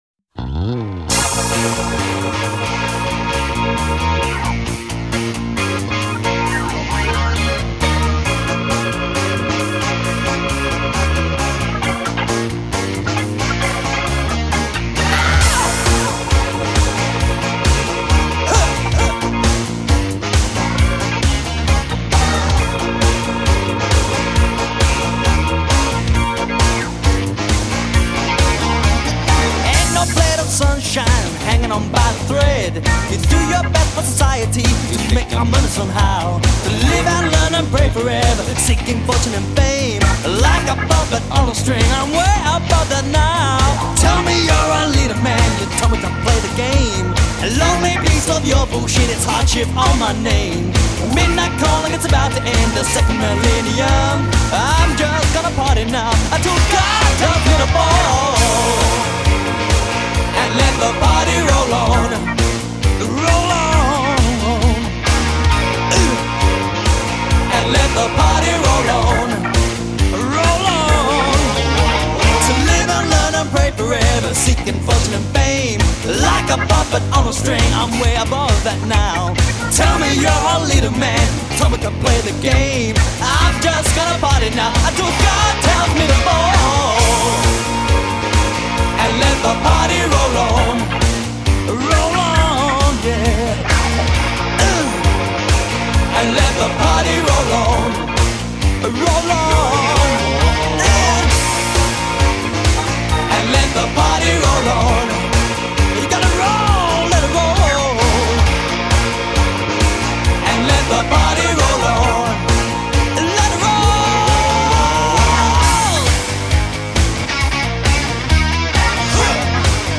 singer and guitarist
Drums
Bass
Keyboards